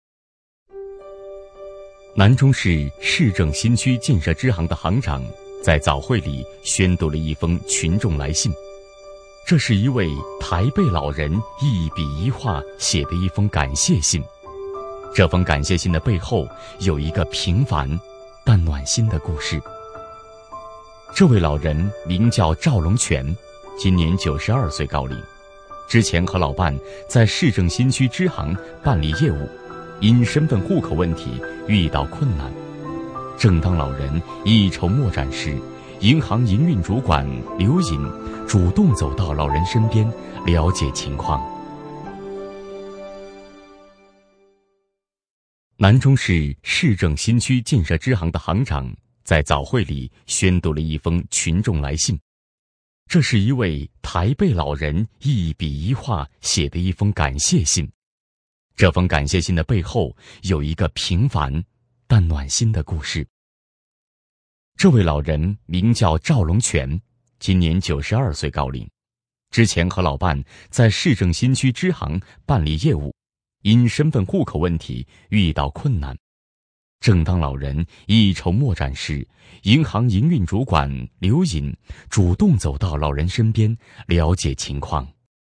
专题男配
【男8号专题】温暖最美建行人